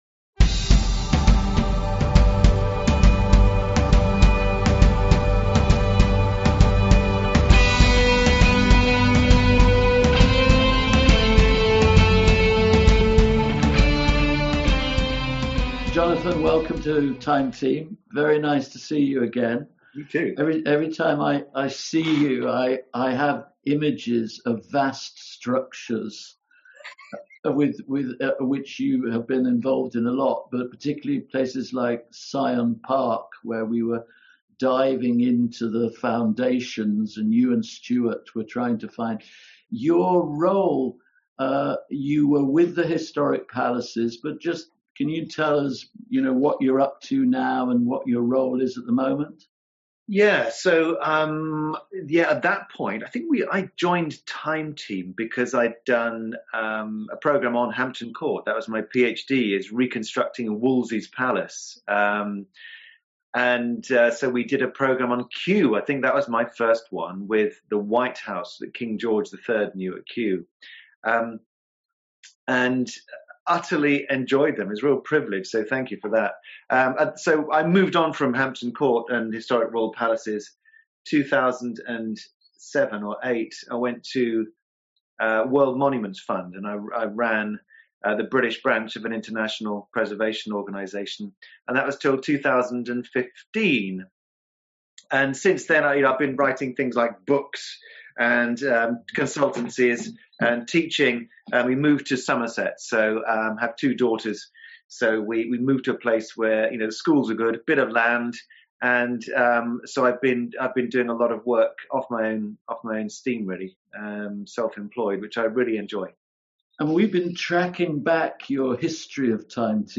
Join us for an exclusive interview with architectural historian, Dr Jonathan Foyle.